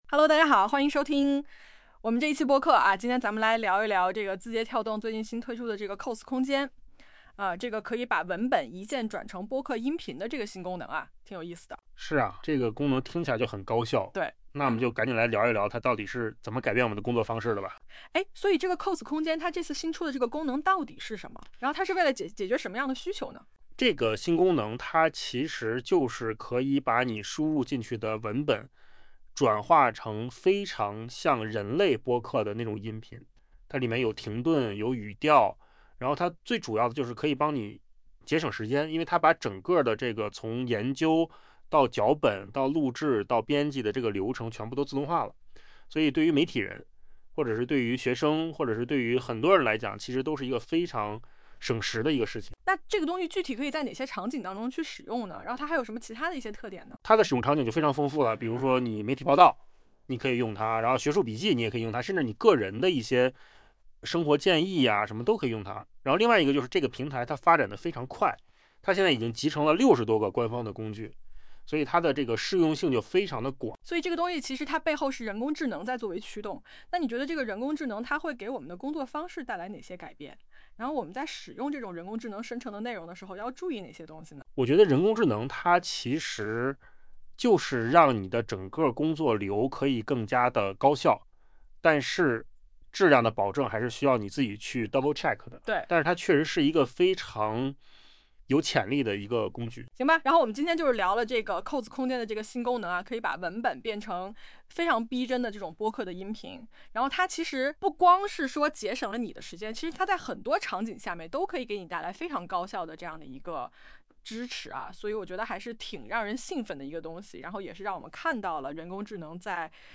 你敢信吗？上面这段播客竟然是AI生成的。
不得不赞叹，扣子空间生成的播客和真人录制的语音效果几乎没有区别了，AI免费打工的图景正成为现实。